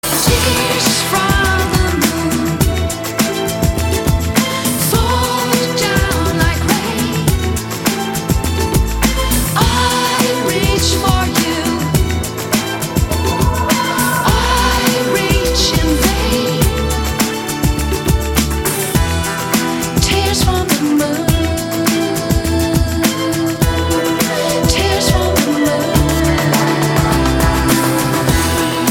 поп
женский вокал
dance
спокойные
Trance
саундтрек